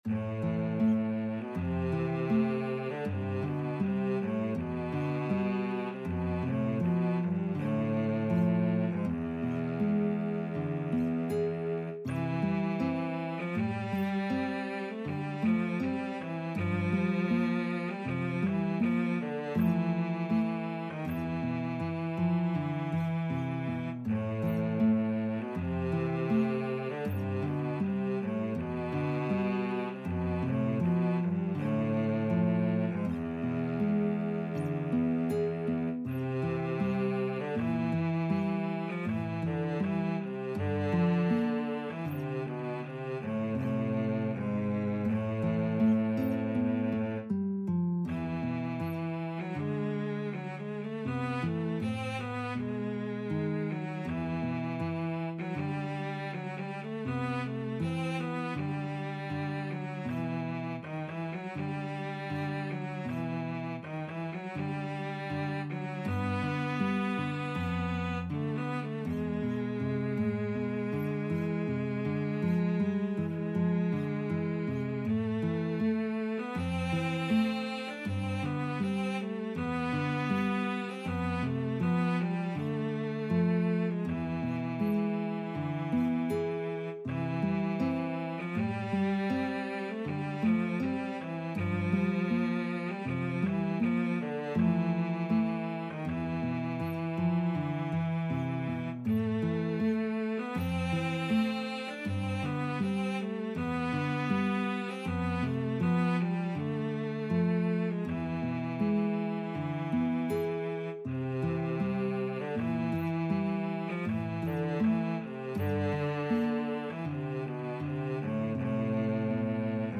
4/4 (View more 4/4 Music)
Andante
Classical (View more Classical Guitar-Cello Duet Music)